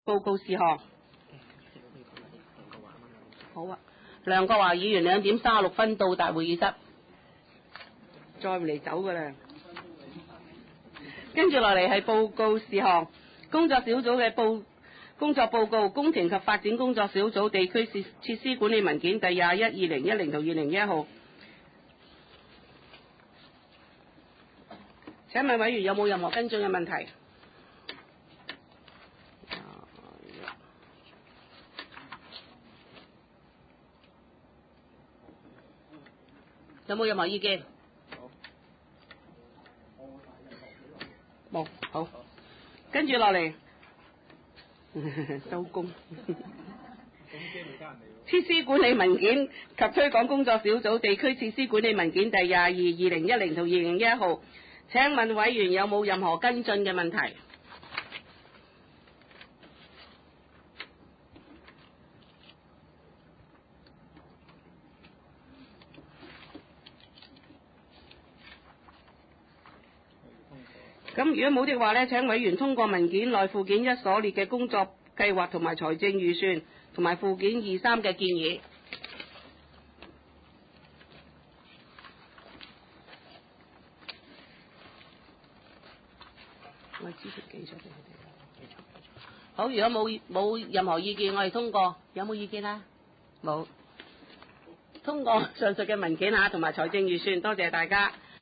葵青民政事務處會議室